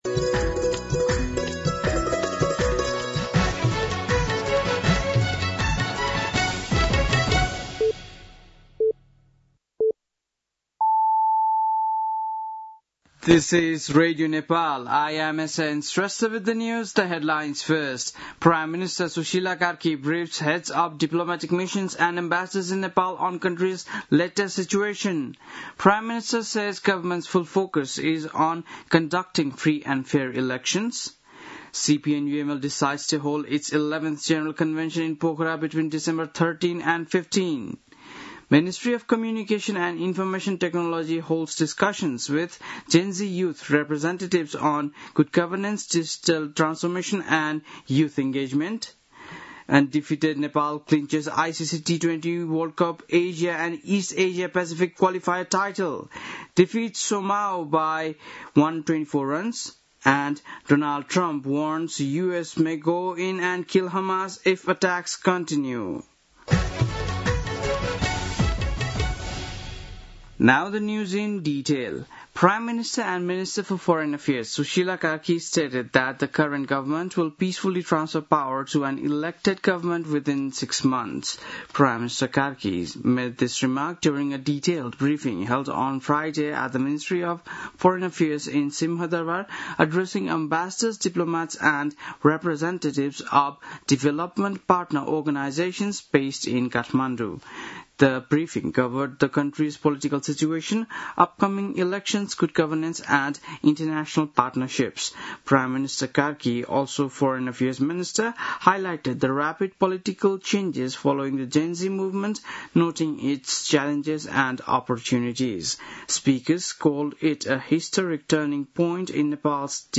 बेलुकी ८ बजेको अङ्ग्रेजी समाचार : ३१ असोज , २०८२